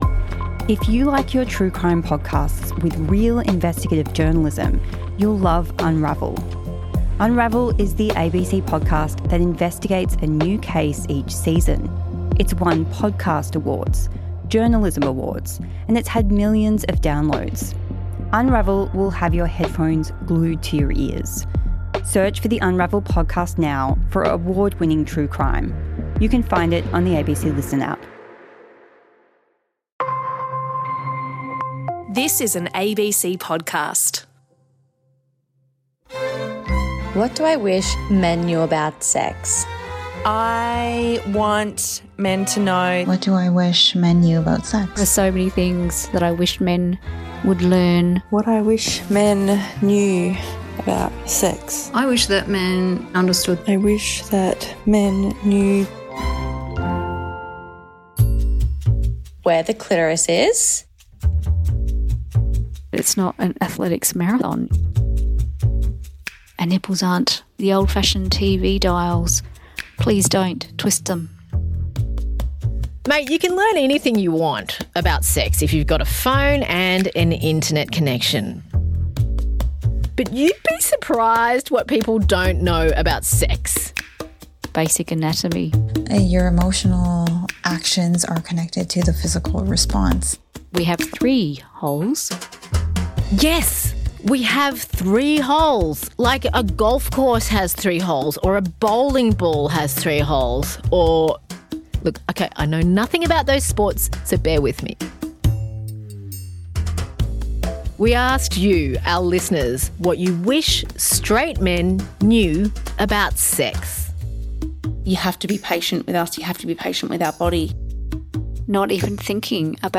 KISC My Health Radio Show interview